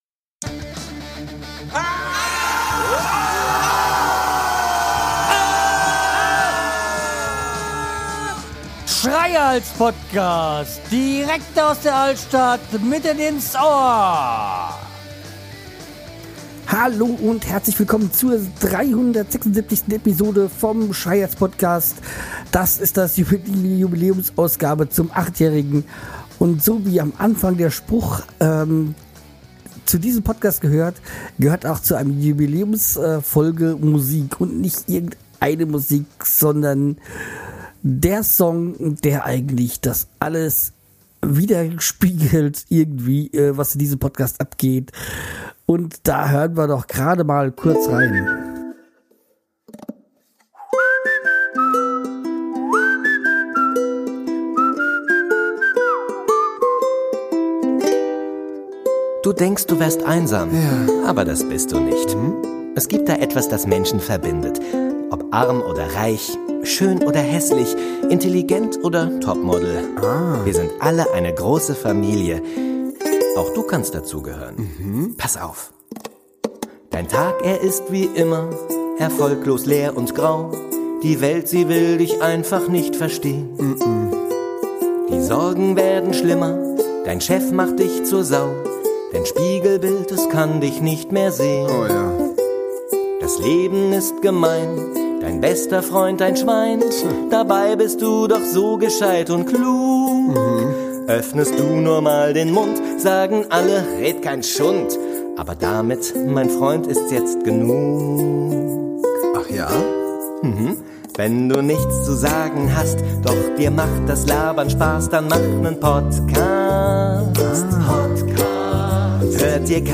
Über einige rede ich heute und 5 Podcaster bzw. Podcasts habe ich mehr oder weniger gut imitiert und möchte von Euch in einem Quiz wissen wer es war.